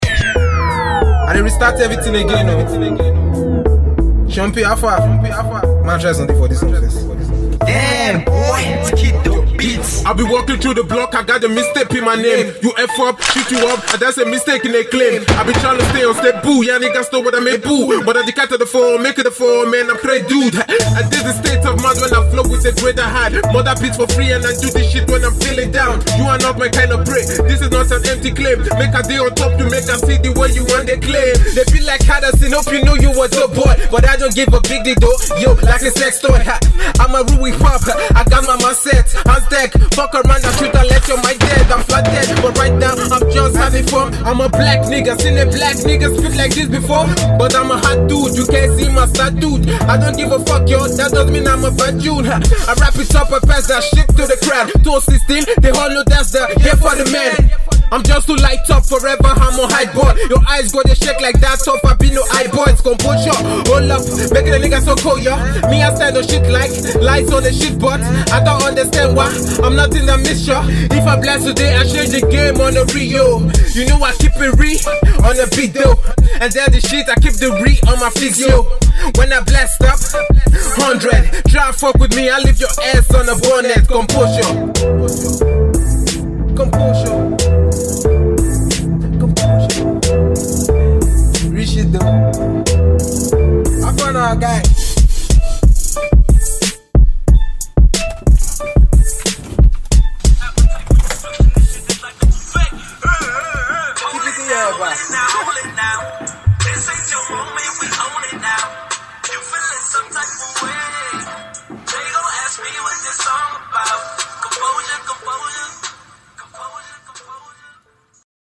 solid rap freestyle over the instrumental